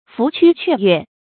鳧趨雀躍 注音： ㄈㄨˊ ㄑㄩ ㄑㄩㄝˋ ㄩㄝˋ 讀音讀法： 意思解釋： 象野鴨那樣快跑，象鳥雀那樣跳躍。